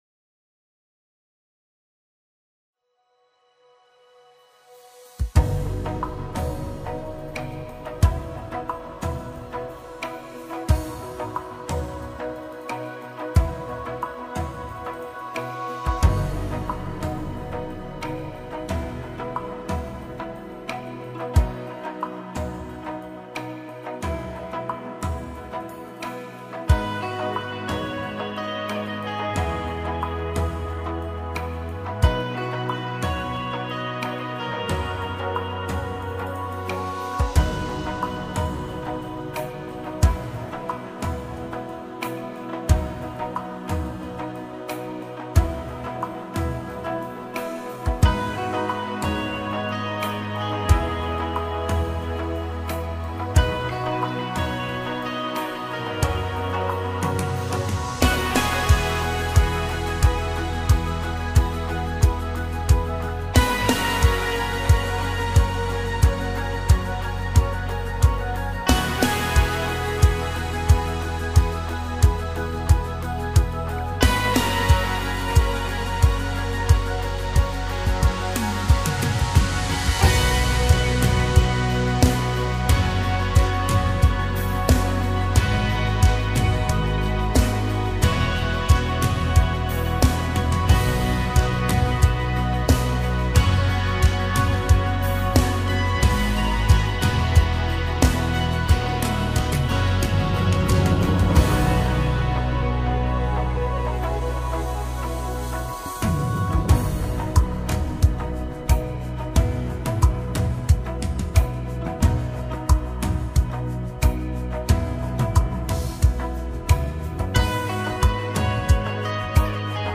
157 просмотров 385 прослушиваний 11 скачиваний BPM: 90